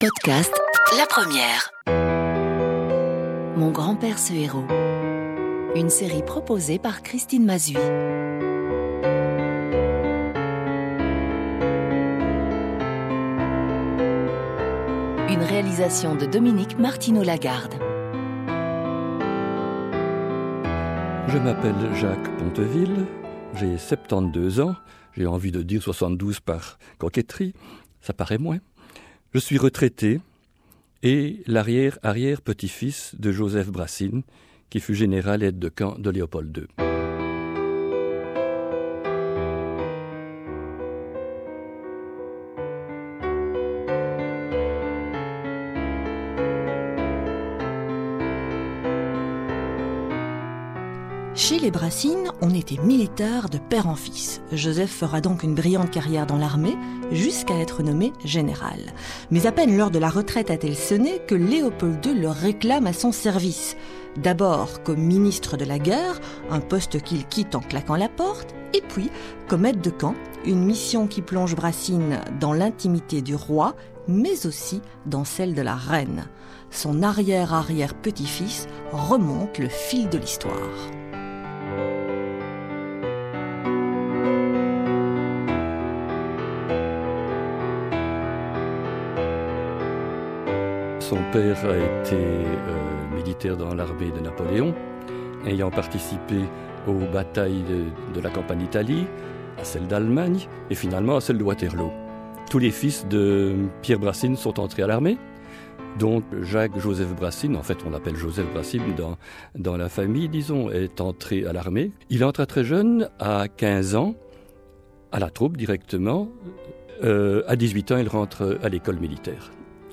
sur les antennes de la radio